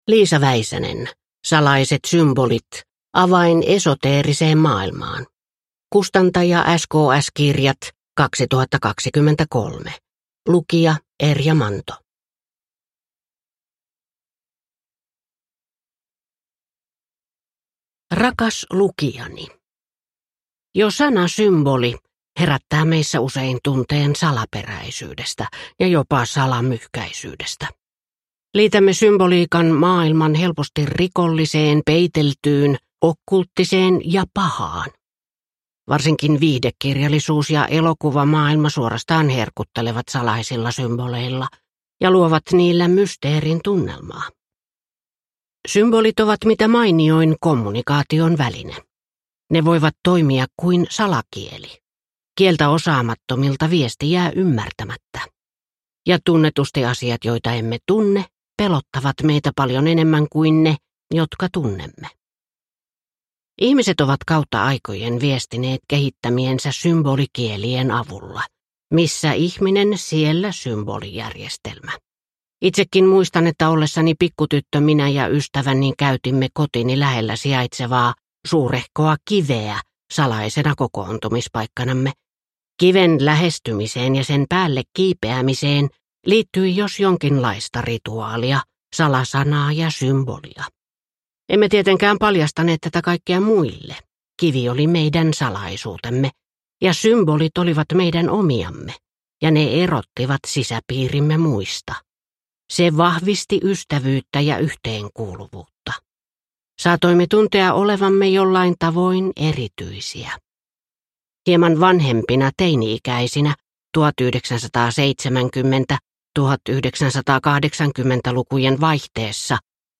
Salaiset symbolit – Ljudbok – Laddas ner